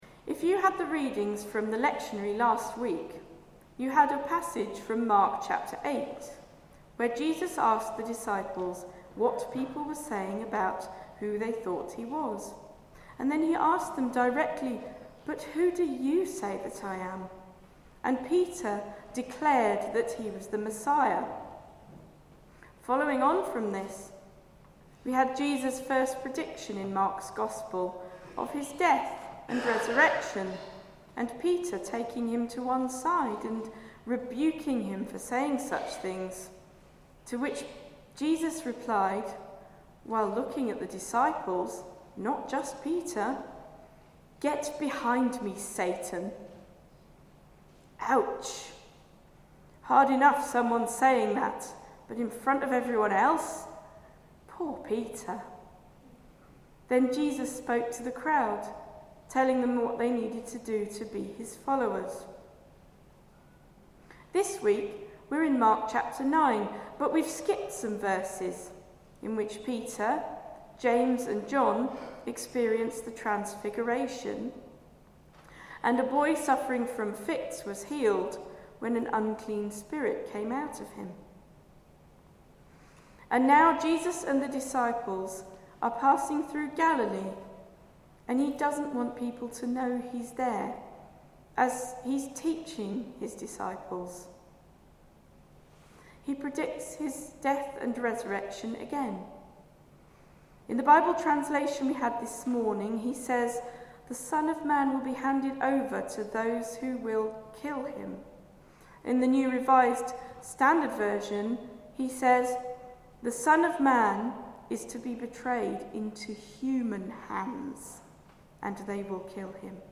Greatness or Wisdom? Sermon preached on 22 September 2024